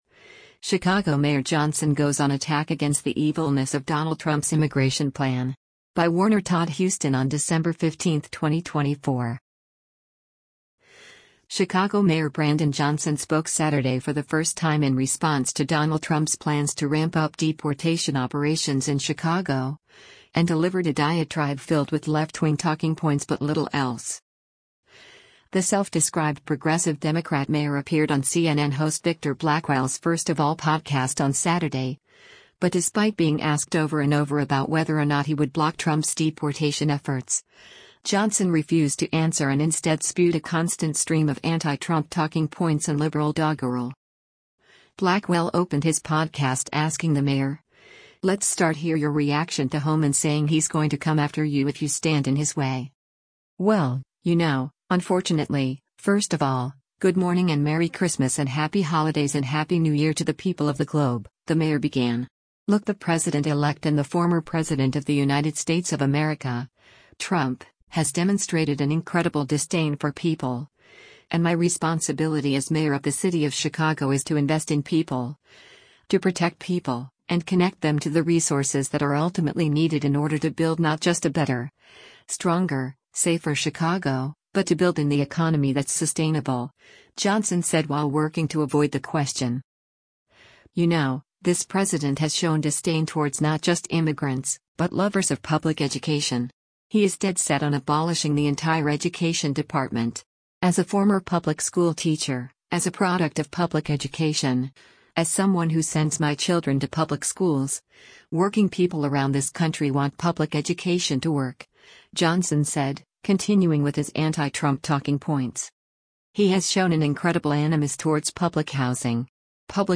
The self-described “progressive” Democrat mayor appeared on CNN host Victor Blackwell’s “First of All” podcast on Saturday, but despite being asked over and over about whether or not he would block Trump’s deportation efforts, Johnson refused to answer and instead spewed a constant stream of anti-Trump talking points and liberal doggerel.
Blackwell then played a clip of Trump border czar Tom Homan, who said he was asking for Democrat-controlled police departments to let Immigration and Customs Enforcement (ICE) agents know the whereabouts of criminal illegals.